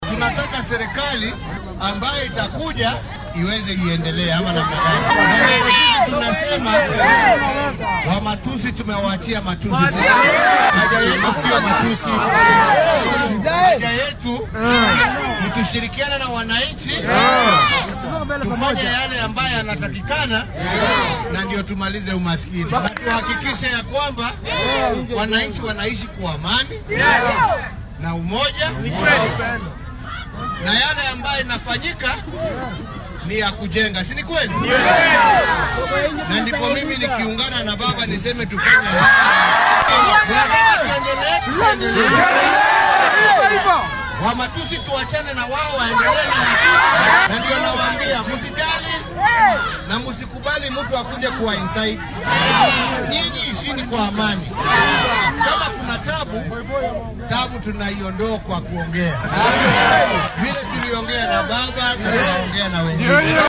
Madaxweynaha dalka Uhuru Kenyatta ayaa xilli uu ku sugnaa suuqa Kenyatta ee magaalada Nairobi dadweynaha u sheegay in aflagaado kasta oo loo gaysto aynan wax saameyn ah ku yeelan doonin waxqabadkiisa.